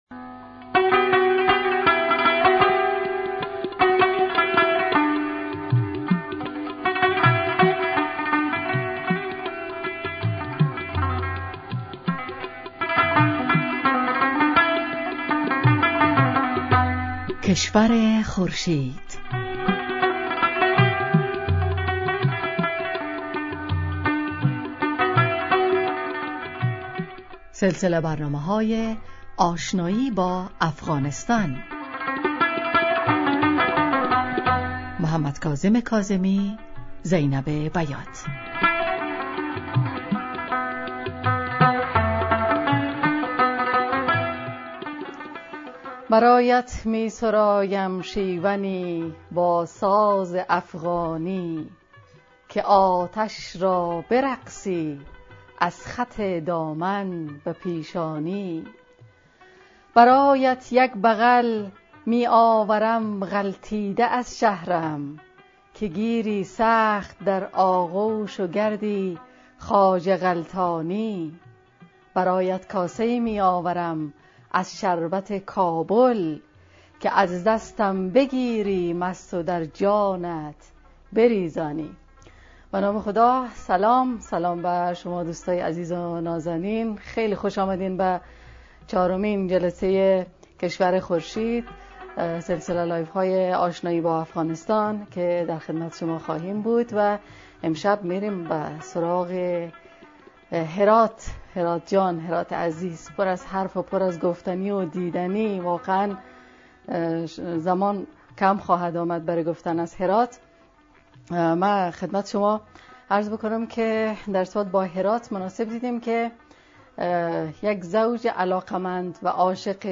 سلسله نشست‌های «کشور خورشید» به صورت زنده در اینستاگرام برگزار می‌شود. موضوع این نشست‌ها، آشنایی با افغانستان است و در هر برنامه جلوه‌هایی از تاریخ، جغرافیا، آثار باستانی، طبیعت، مردم و مفاخر افغانستان معرفی می‌شود.